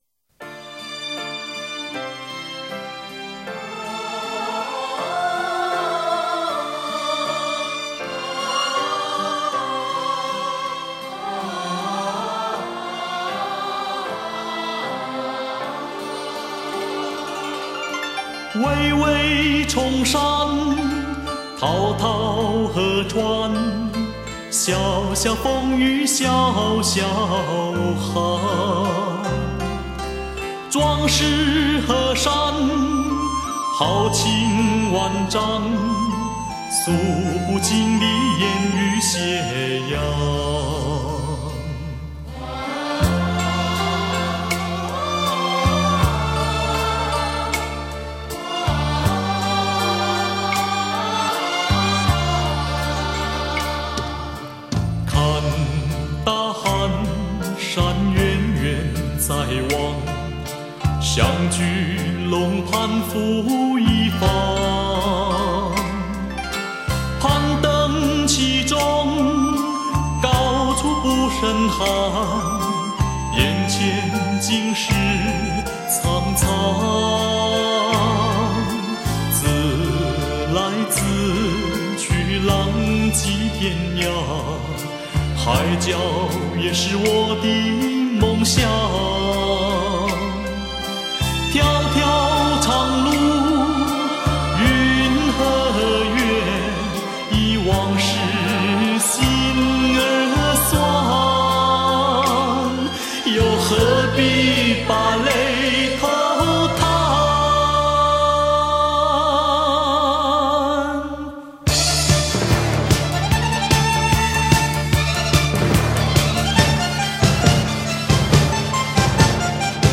他的嗓音属于雄浑壮伟的类型，演唱技巧老练纯熟，再加上他独家特
持在偏重民族风的路线上，歌路稳健雄浑，大情大性，曲风阳刚气盛